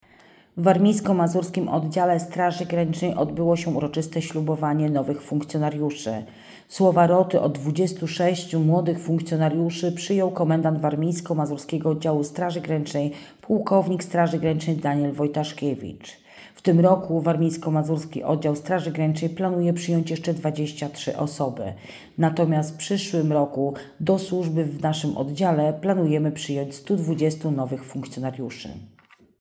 Ślubowanie i awanse w Warmińsko-Mazurskim Oddziale Straży Granicznej
Słowa roty od 26 nowych funkcjonariuszy przyjął Komendant Warmińsko-Mazurskiego Oddziału Straży Granicznej płk SG Daniel Wojtaszkiewicz.
slubowanie.mp3